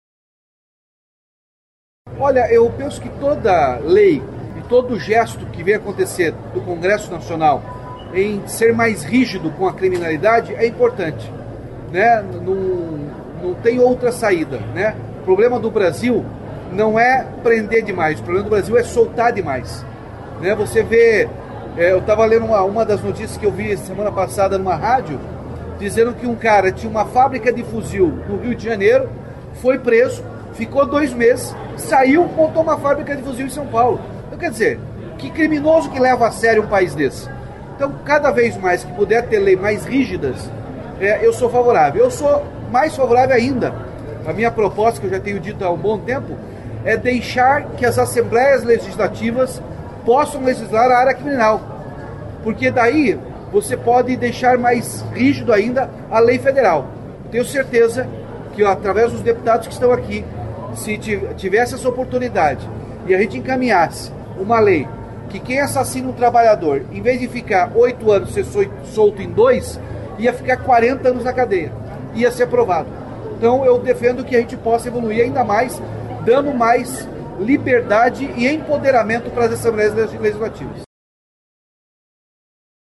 Na mesma entrevista, Ratinho Junior também defendeu o avanço, no Congresso Nacional, do projeto que estabelece penas mais duras para integrantes de facções criminosas. Ele classificou o texto como fundamental para endurecer o combate ao crime organizado e disse considerar que o país não enfrenta um problema de excesso de prisões, mas de excessos nas liberações.